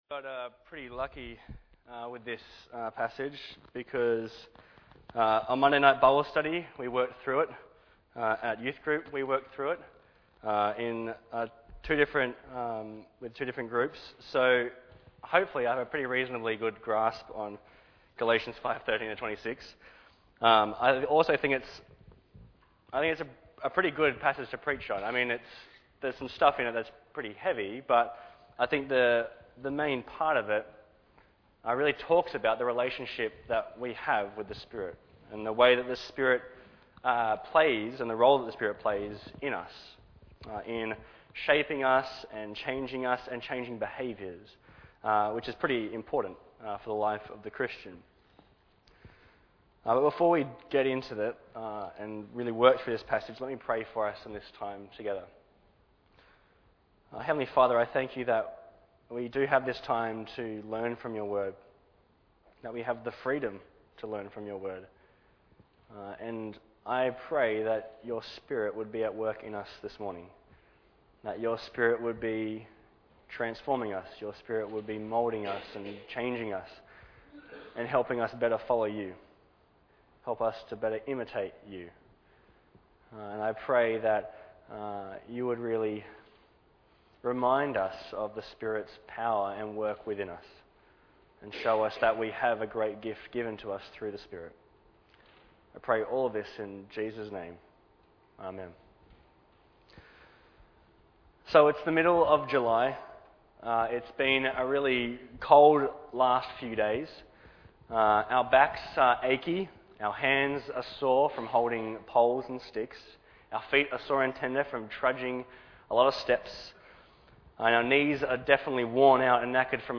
Bible Text: Galatians 5:13-26 | Preacher